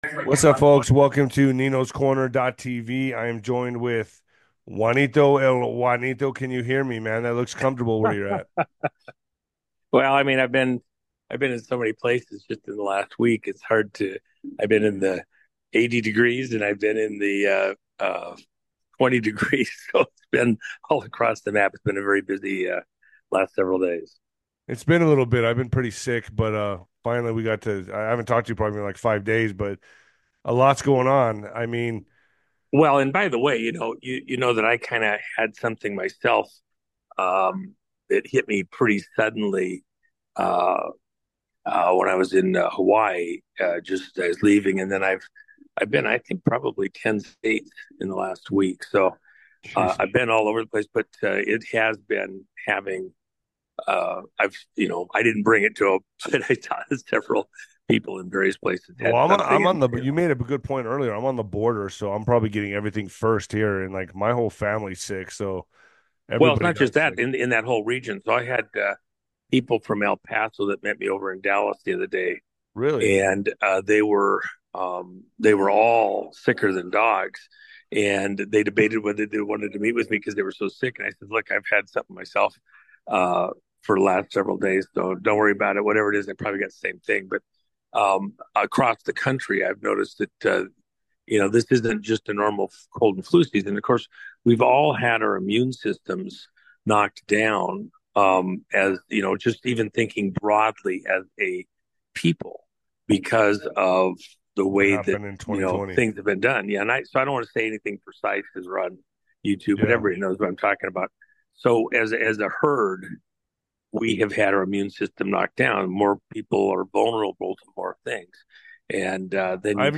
the hosts chat about their travels, health, and politics. They talk about a "shadow cabinet" and worries about Russia.